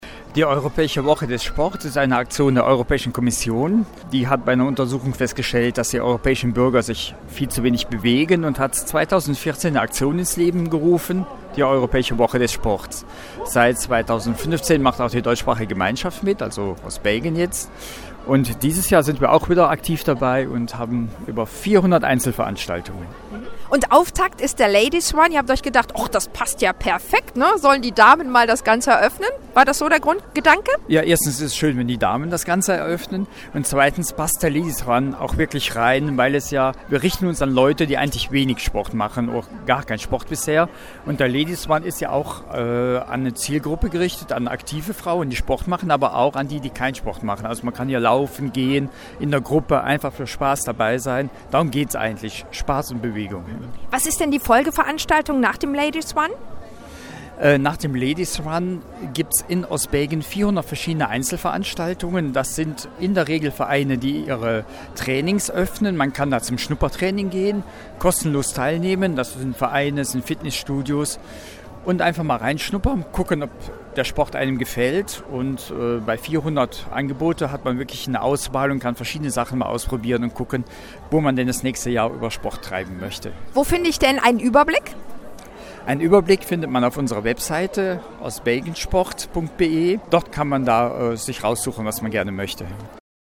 Unter dem Motto BeActive ging gestern die Europäische Woche des Sports an den Start. Auftaktveranstaltung war der Ladies Run in Eupens Innenstatdt.